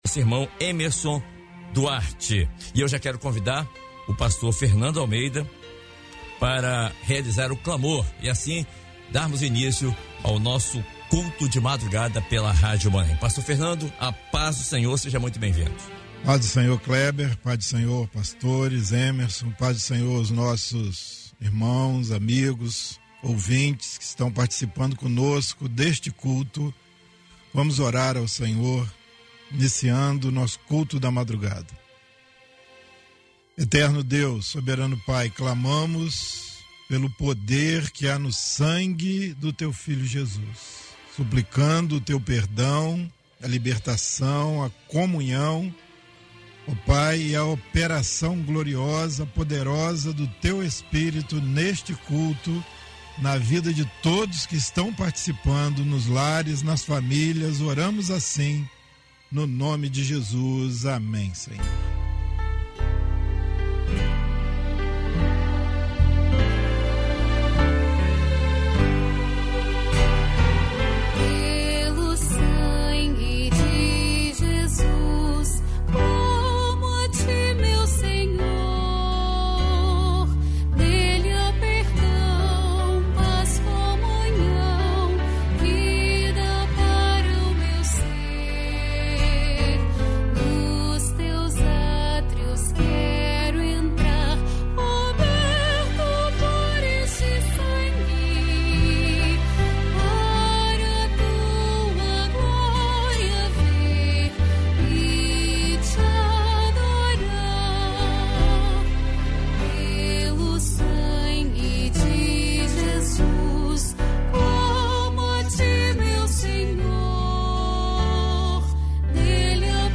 Culto de oração transmitido dentro do programa Bom Dia Maanaim